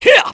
Roman-Vox_Attack2_kr.wav